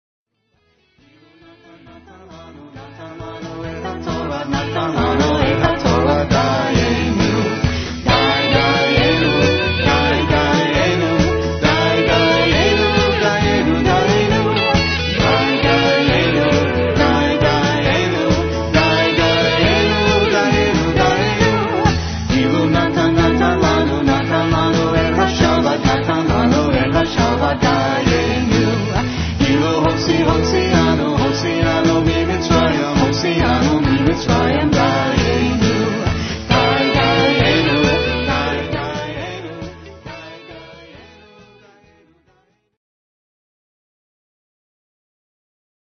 Simcha is the premiere Southwest Florida Jewish music band with an  extensive repertoire of  traditional  Israeli music,